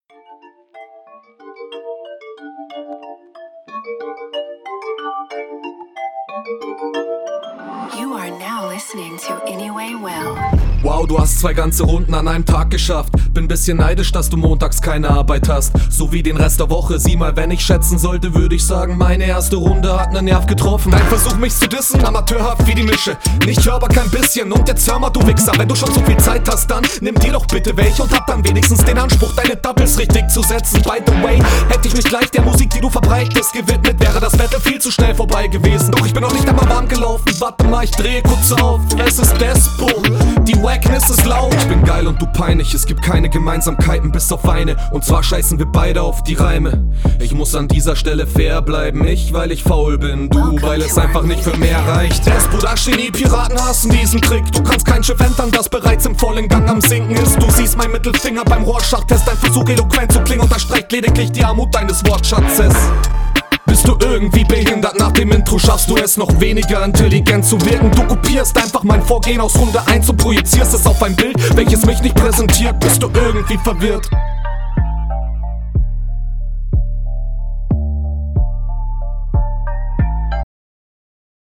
Ahh, schöne Mische.